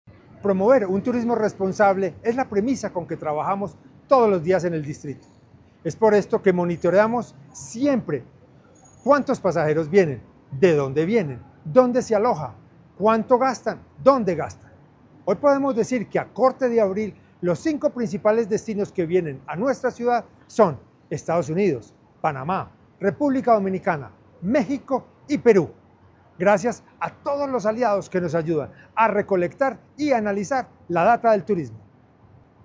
Audio-Declaraciones-del-secretario-de-Cultura-y-Turismo-Jose-Alejandro-Gonzalez.mp3